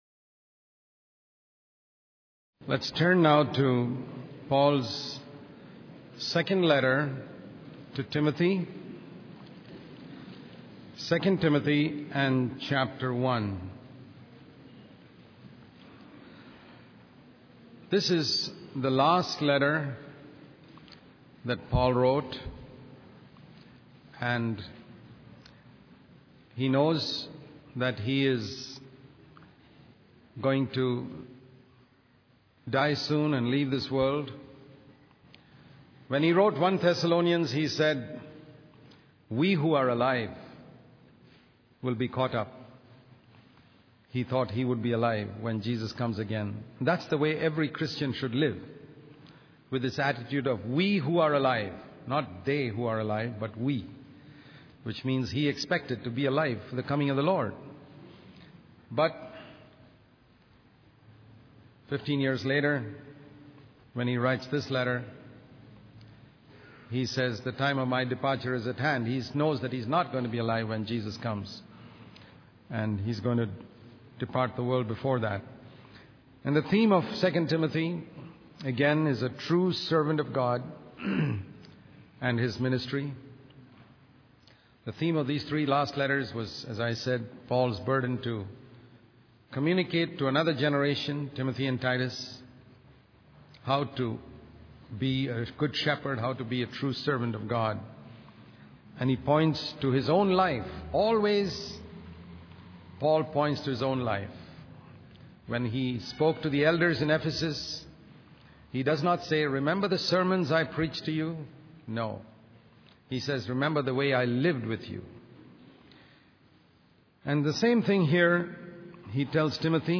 In this sermon, the speaker focuses on the characteristics of a true servant of God, as outlined in the book of 2 Timothy. He emphasizes the importance of being a faithful teacher of God's word and entrusting it to future generations. The speaker encourages believers not to lower the standard of the scriptures in order to attract more people to their churches, but rather to prioritize a higher standard even if it means having fewer followers.